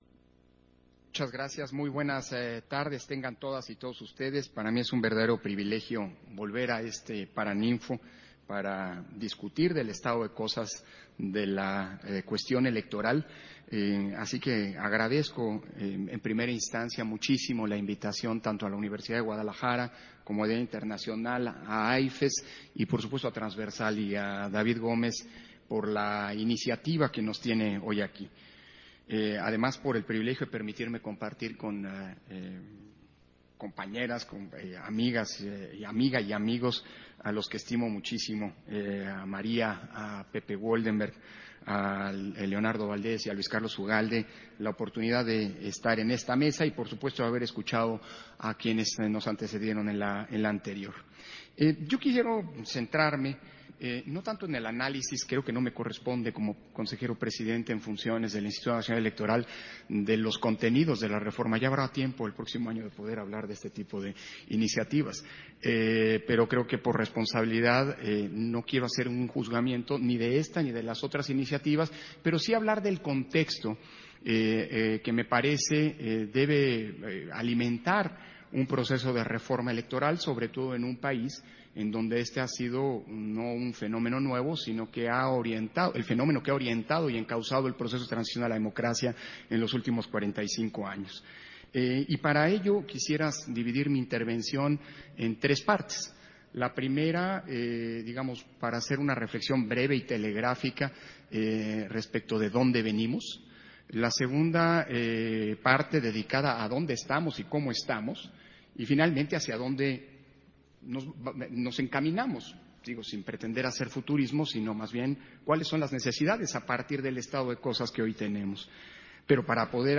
Intervención de Lorenzo Córdova, durante el Foro Internacional en Defensa del INE y de la Democracia Mexicana